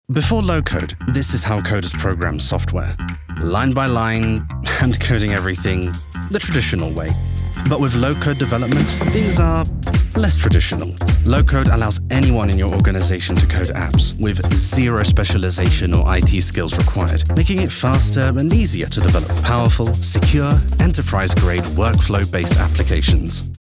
Versatile English voice over, ranging from warm and engaging to theatrical, funny or conversational.
Sprechprobe: Sonstiges (Muttersprache):